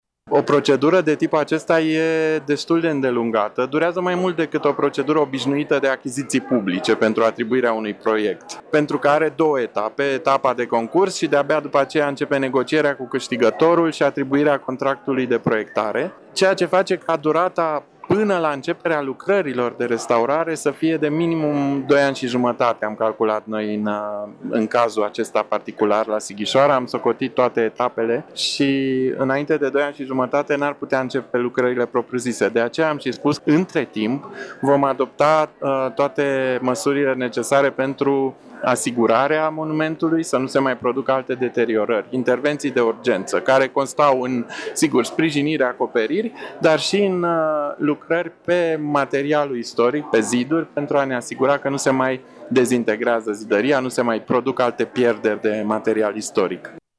Ștefan Bâlici afirmă că lucrările propriu-zise de restaurare nu vor începe mai repede de doi ani și jumătate, iar până atunci se vor executa numai lucrări de urgență: